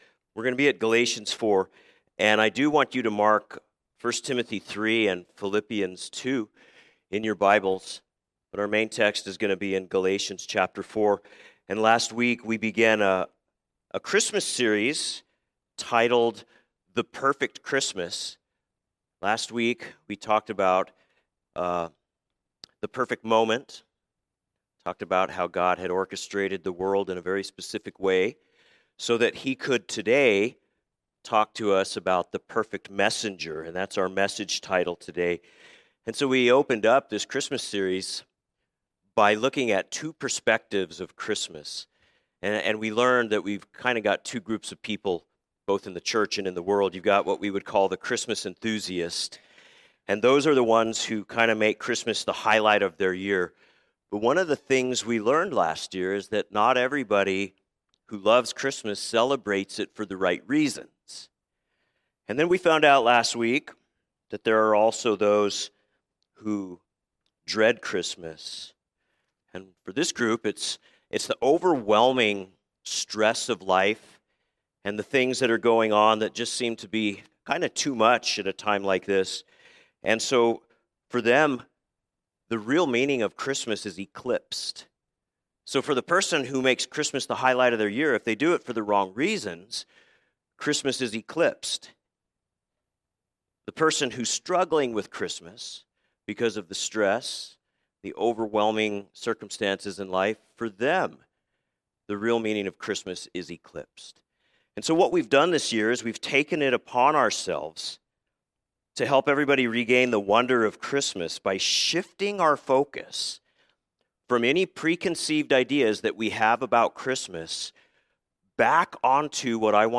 A message from the series "Special Messages."